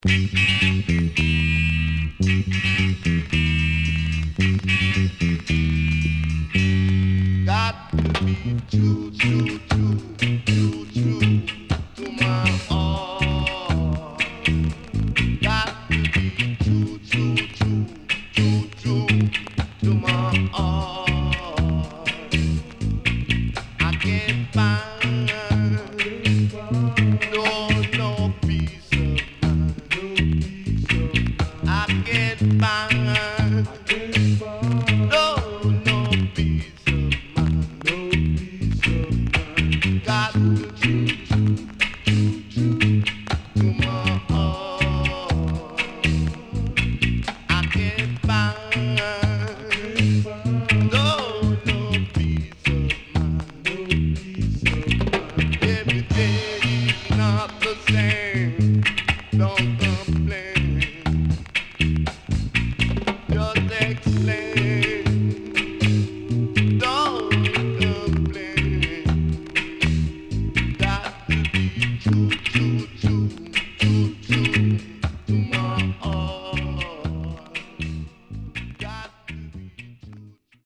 HOME > LOW PRICE [VINTAGE 7inch]
SIDE A:所々小傷による目立つノイズ入ります。